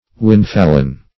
Windfallen \Wind"fall`en\, a. Blown down by the wind.